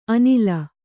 ♪ anila